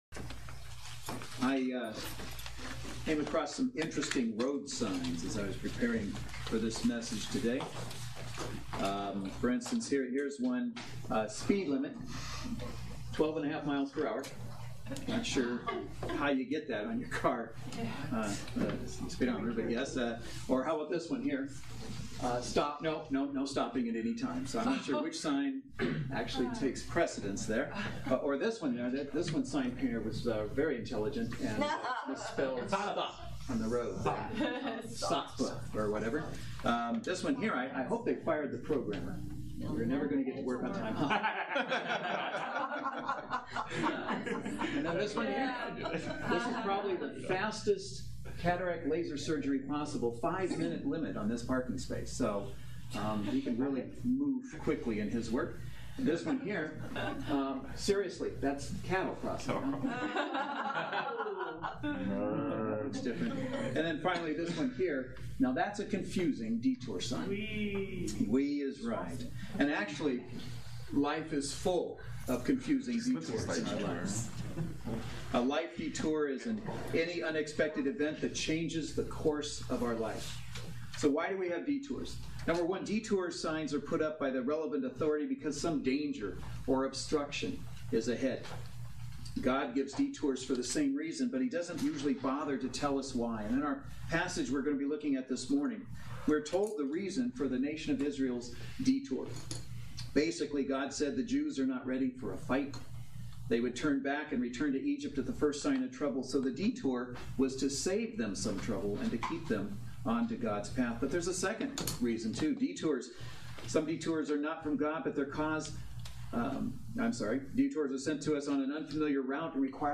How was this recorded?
God of the Movement Service Type: Saturday Worship Service Speaker